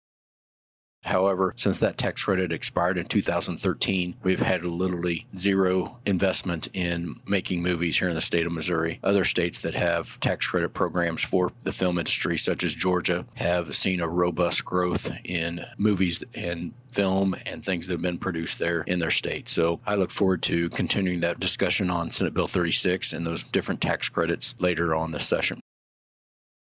1. Senator Hoskins says Senate Bill 36 is legislation that seeks to establish the Capitol Complex Tax Credit Act. This measure includes a filmmaker tax credit.